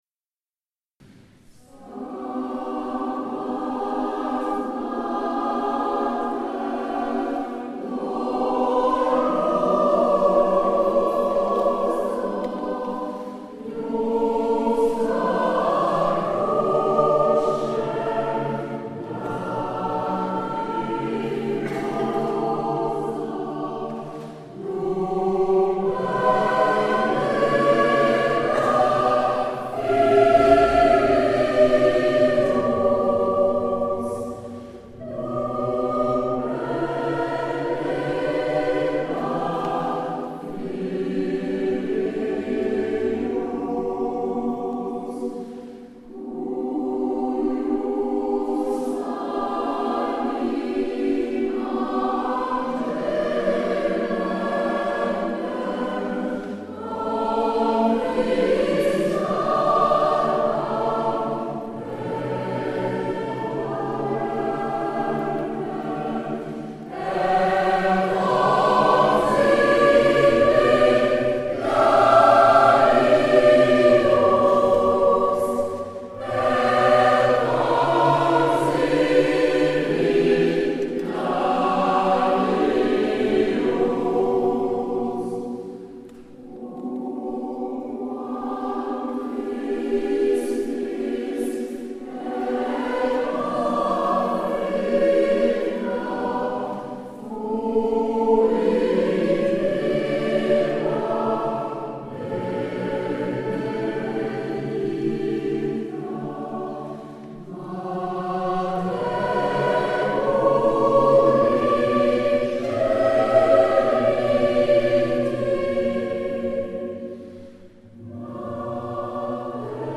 Quelques enregistrements effectués lors de nos concerts